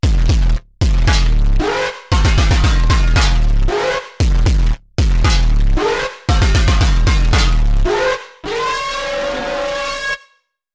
That’s properly acidic.